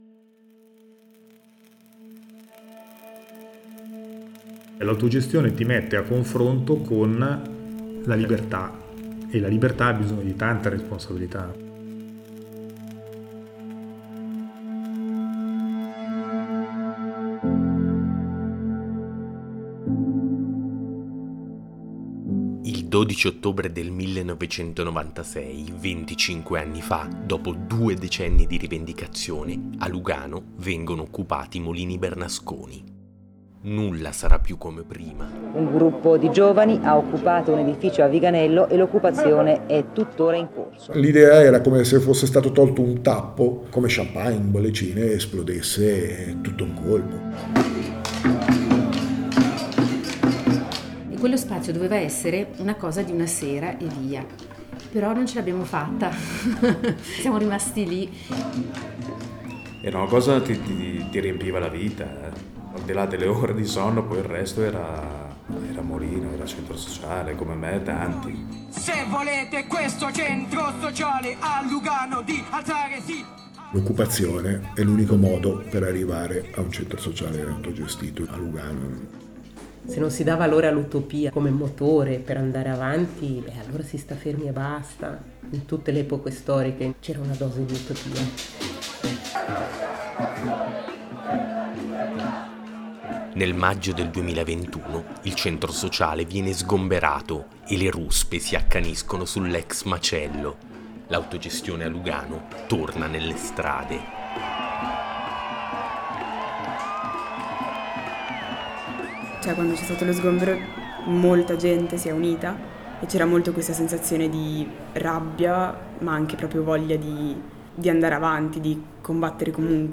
Il podcast Macerie ricostruisce, attraverso tante voci, le vicende di una realtà che ha inciso profondamente nello spazio culturale, sociale e politico del Ticino.
Der Podcast Macerie rekonstruiert mit vielen Stimmen die Wandlungen dieser Institution, die den kulturellen, sozialen und politischen Raum des Tessins massgeblich geprägt hat.